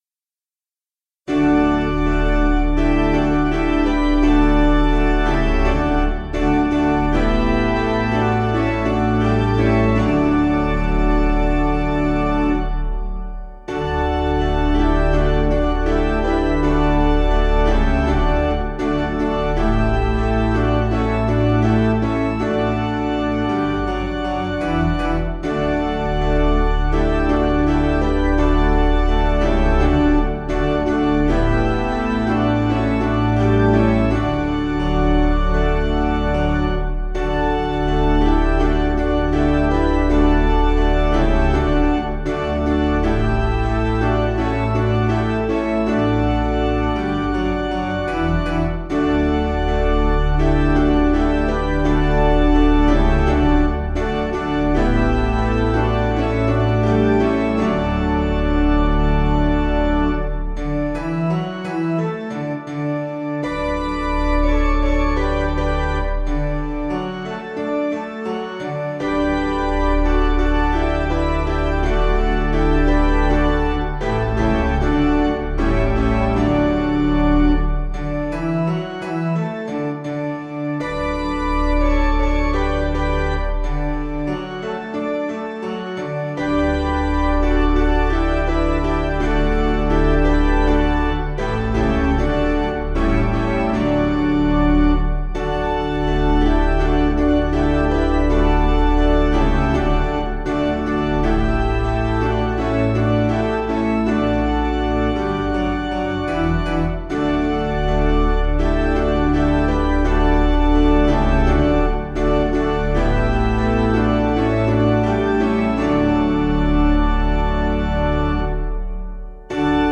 Composer: Ratany
Key: D Major